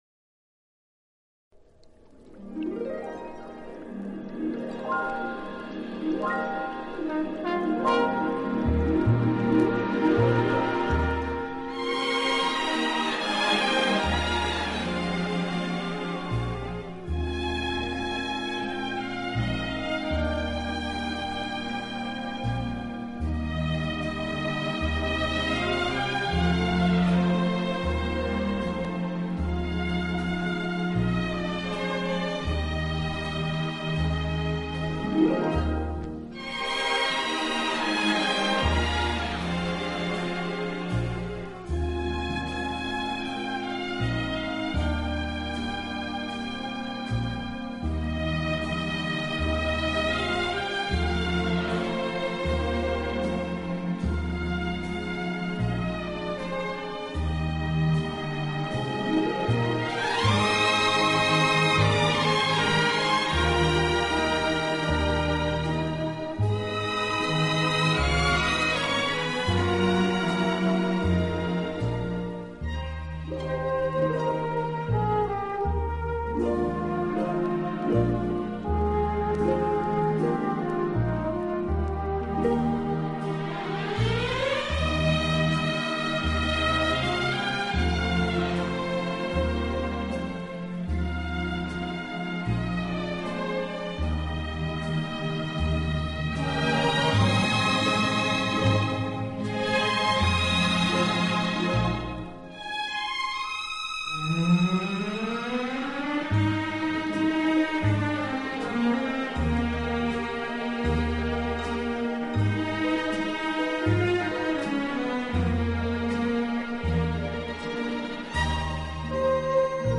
轻快、节奏鲜明突出，曲目以西方流行音乐为主。